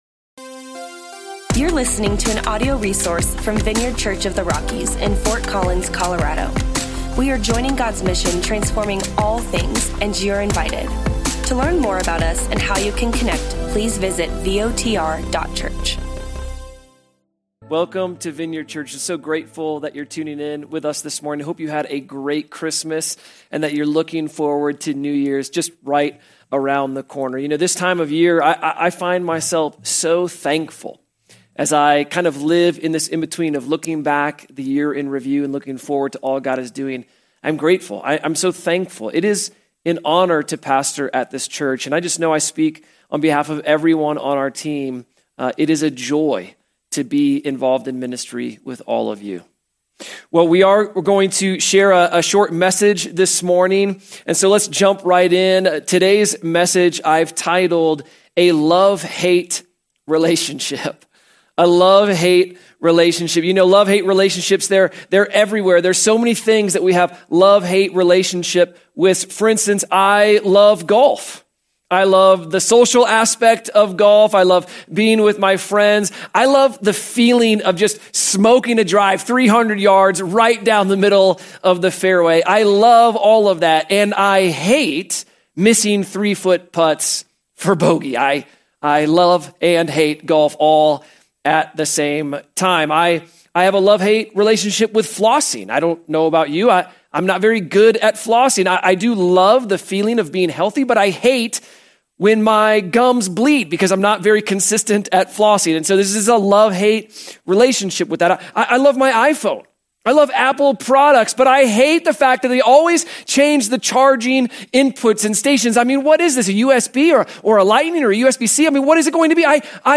Join us for our online-only service as we wrap up 2024.